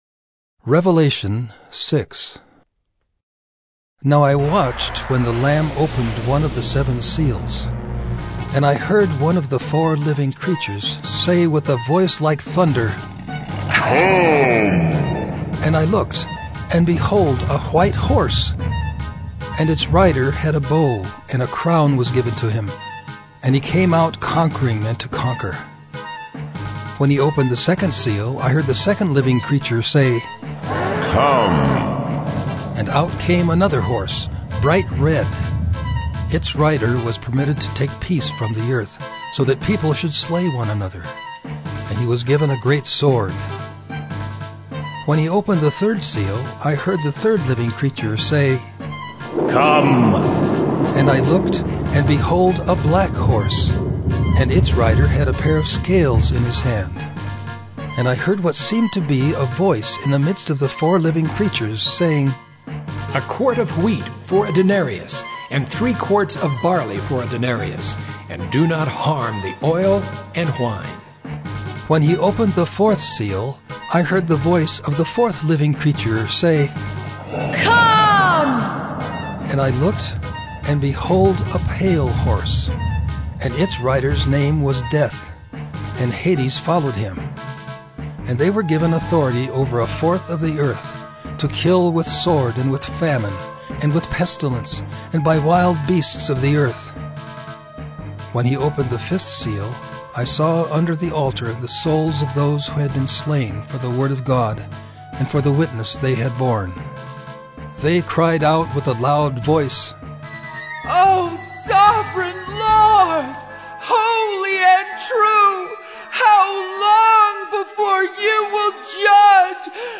“Listening to God” Bible Reading & Devotion: Mar. 3, 2022 – Revelation 006